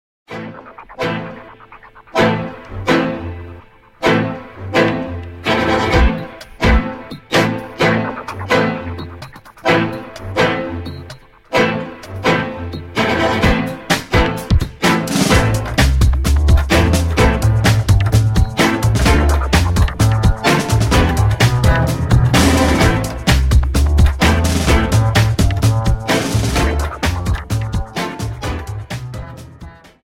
Dance: Tango 32 Song